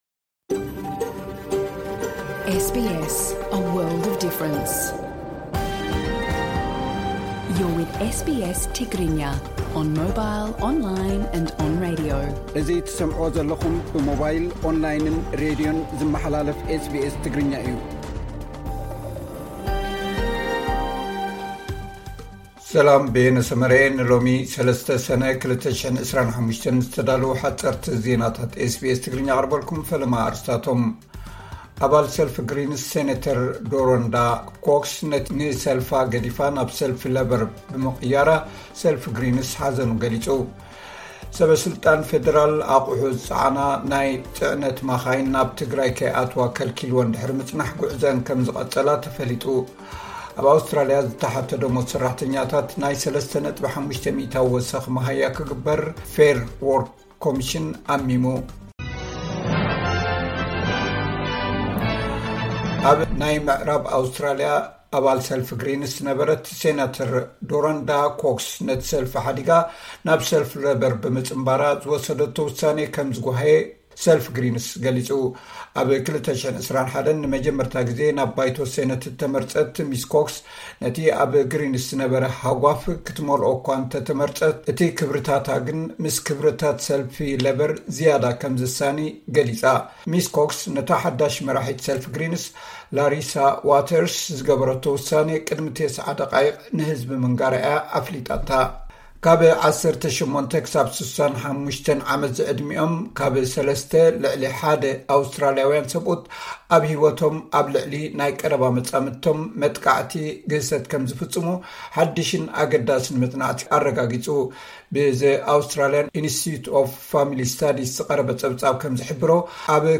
ሓጸርቲ ዜናታት ኤስ ቢ ኤስ ትግርኛ (03 ሰነ 2025)